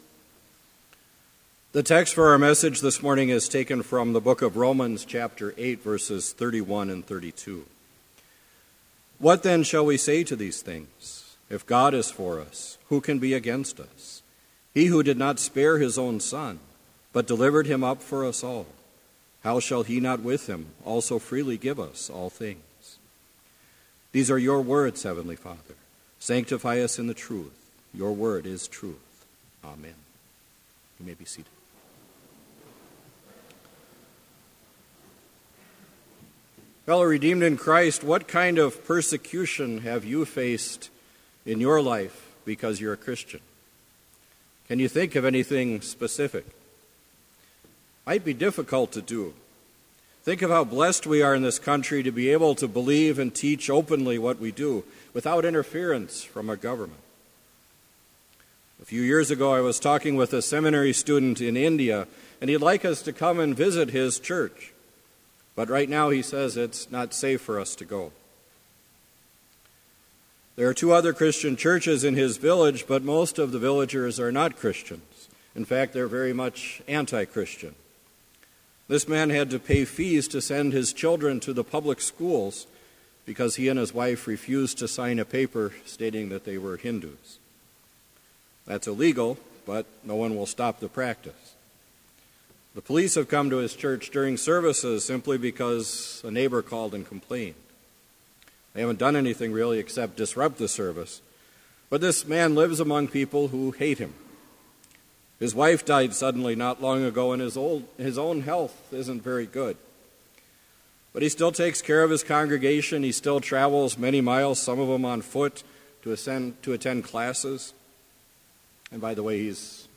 Complete Service
This Chapel Service was held in Trinity Chapel at Bethany Lutheran College on Tuesday, April 5, 2016, at 10 a.m. Page and hymn numbers are from the Evangelical Lutheran Hymnary.